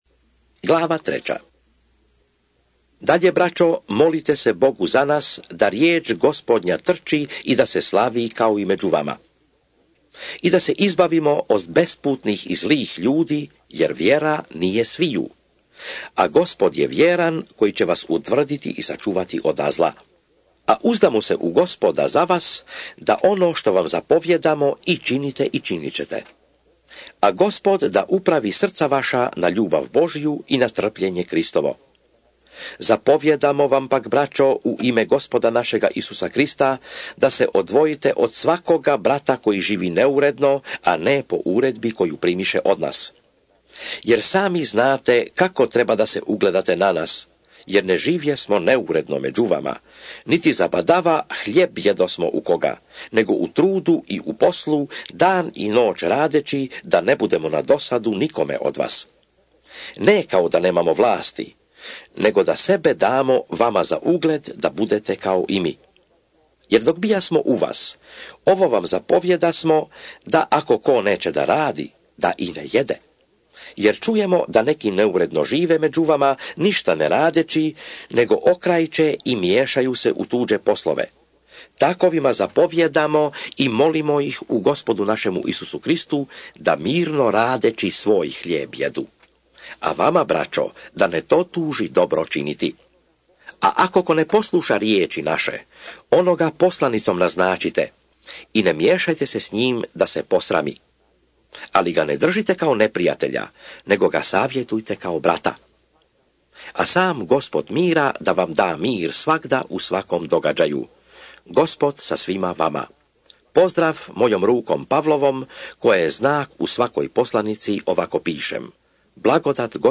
2 SOLUNJANI(ČITANJE) - Bible expounded
SVETO PISMO – ČITANJE – Audio mp3 2 SOLUNJANI glava 1 glava 2 glava 3